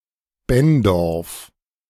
Bendorf (German pronunciation: [ˈbɛndɔʁf]
De-Bendorf.ogg.mp3